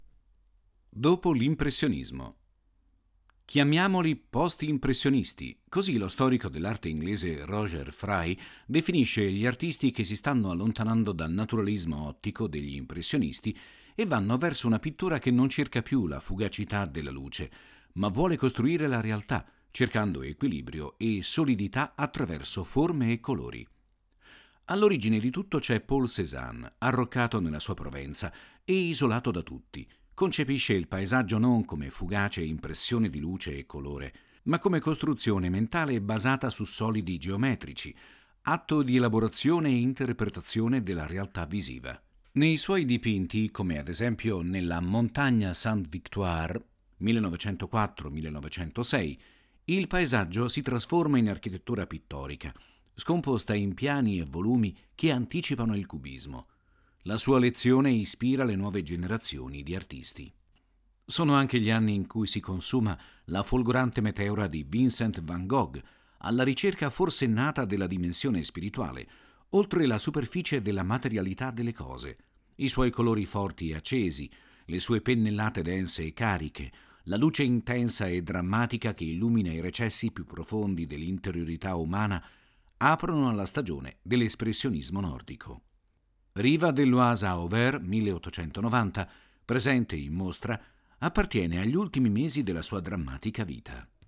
• 5 AUDIODESCRIZIONI DI SEZIONE che accompagnano il visitatore nel percorso espositivo, fruibili tramite QR code